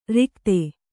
♪ rikte